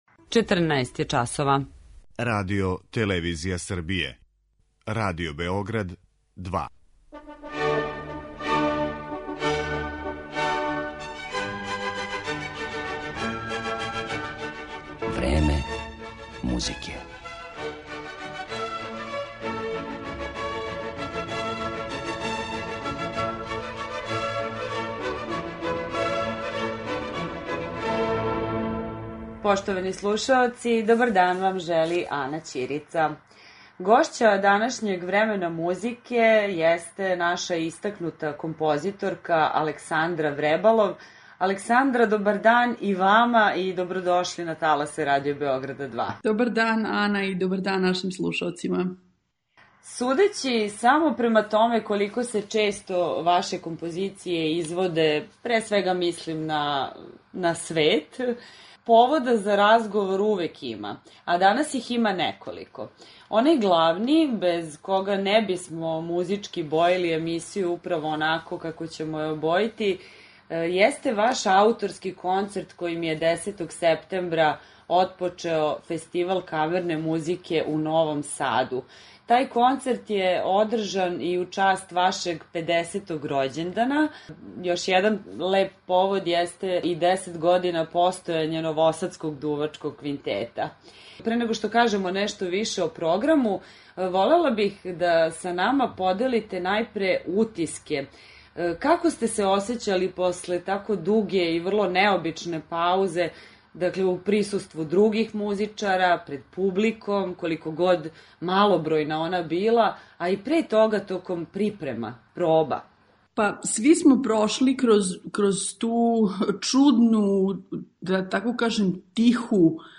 Током Времена музике, поред разговора са Александром Вребалов, емитоваћемо и снимке са концерта којим је био обележен ауторкин 50. рођендан, а којим је 10. септембра у Кутурној станици „Свилара" отворен и Фестивал камерне музике у Новом Саду.